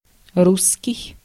Ääntäminen
IPA: [ʁys]